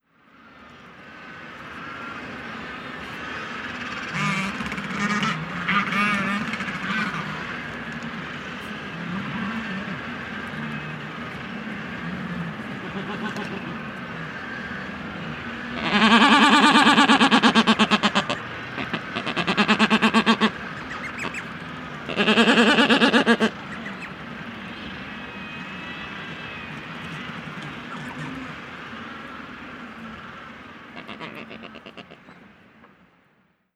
• black-browed albatross and macaroni penguins.wav
black-browed_albatross_and_macaroni_penguins_oRd.wav